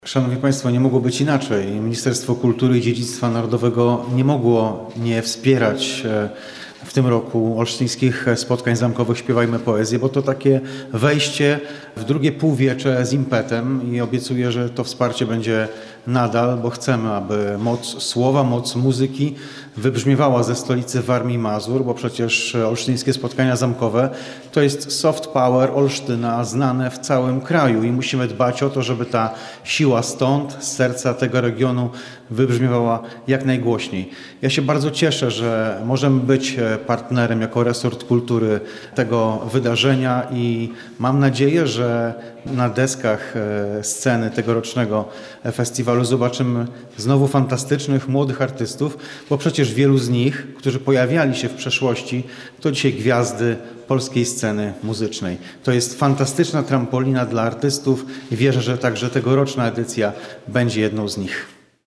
Z dziennikarzami spotkał się także wiceminister kultury i dziedzictwa narodowego – Maciej Wróbel.
– mówił minister.